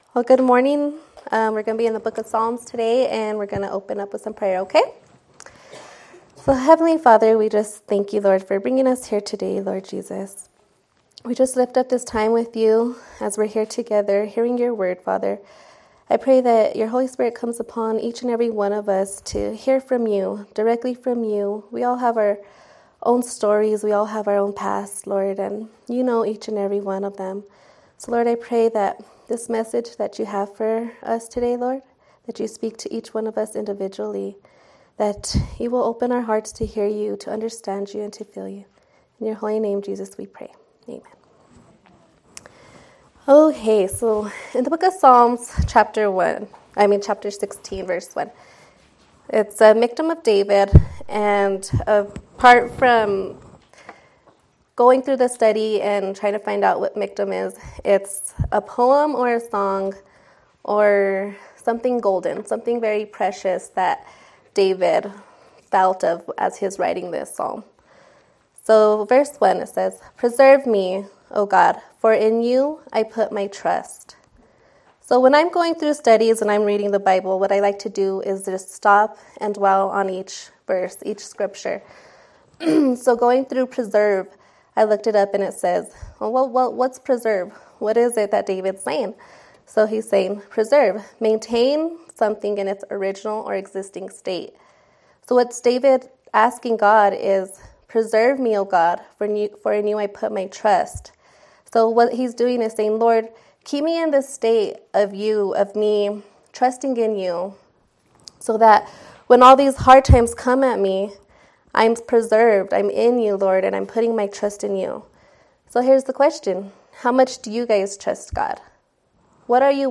Women's Sermons - Sonlit Hills Christian Fellowship